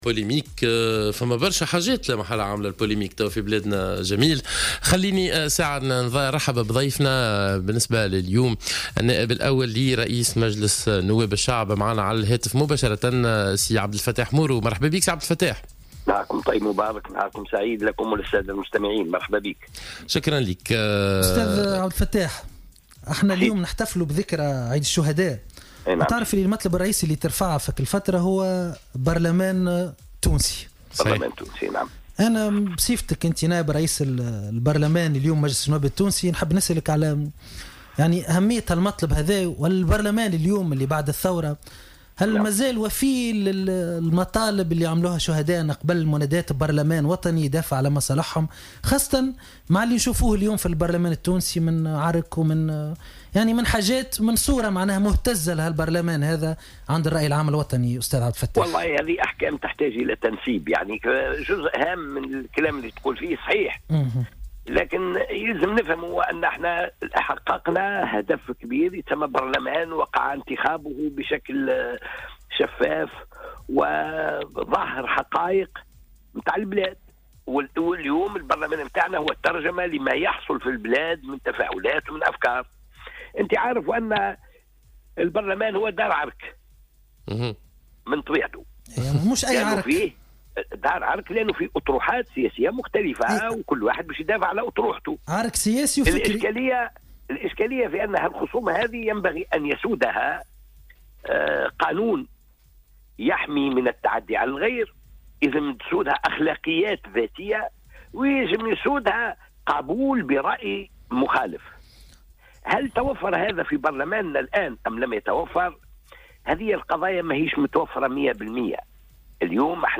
وأضاف مورو، خلال مداخلته في برنامج "صباح الورد" اليوم الاثنين 9 أفريل 2018، أن المجلس في حاجة لأمرين، أولهما مدونة سلوك يلتزم من خلالها النواب بأخلاقيات ذاتية في تعاملهم مع الآخرين، أما الأمر الثاني فهو ضرورة سن قانون داخلي صارم يكون فاصلا بين الجميع تحت قبة باردو وخاصة في النزاعات التي تقوم بينهم.